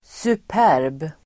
Ladda ner uttalet
superb adjektiv, superb Uttal: [sup'är:b] Böjningar: superbt, superba Synonymer: bäst, finfin, härlig, perfekt, storslagen, strålande, suverän, ultimat, utmärkt, utomordentlig, ypperlig Definition: utomordentligt bra